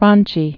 (ränchē)